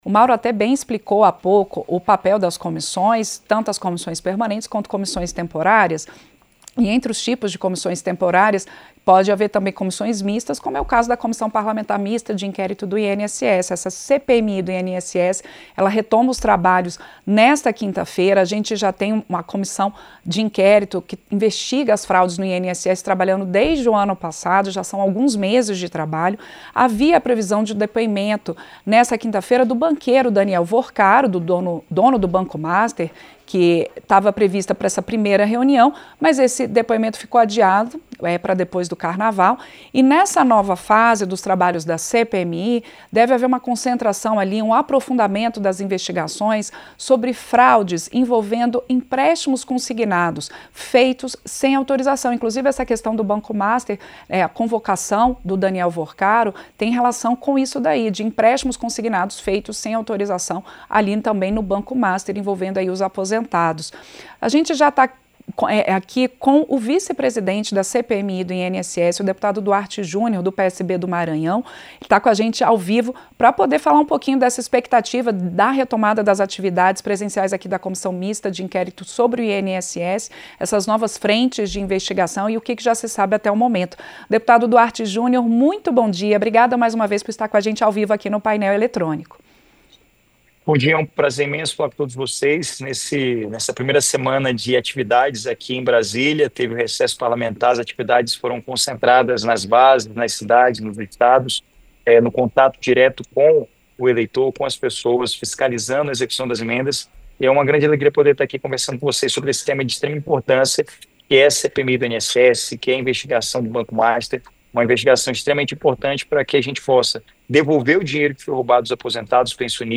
Vice-presidente da CPMI do INSS, o deputado Duarte Jr. (PSB-MA) confirmou que a comissão de inquérito vai avançar sobre investigações relacionadas às denúncias de envolvimento do Banco Master com descontos ilegais em aposentadorias. O deputado falou ao Painel Eletrônico desta quarta-feira (4).
Entrevista – Dep. Duarte Junior (PSB-MA)